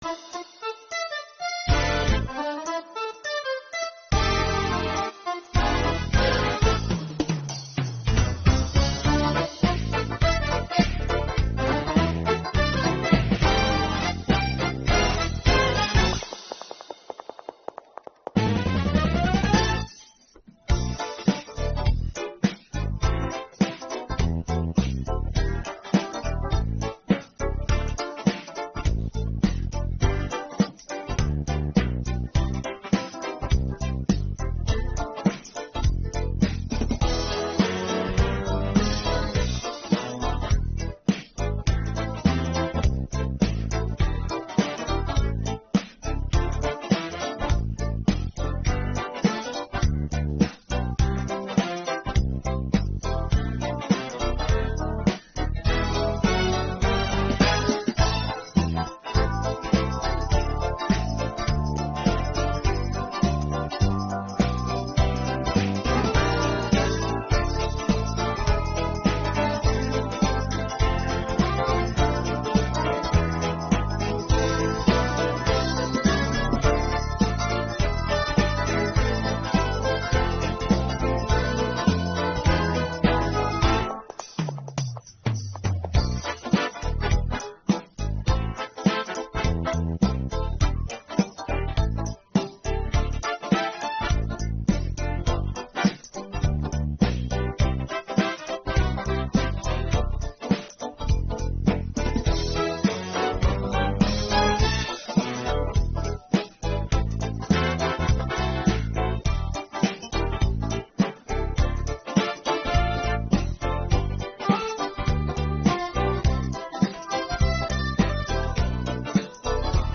Безкоштовна минусовка у стилі караоке для співу онлайн.